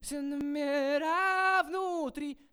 Очень уж явный клик В моих руках проблема исчезать никак не хочет Вложения Клик Номера.wav Клик Номера.wav 438 KB · Просмотры: 134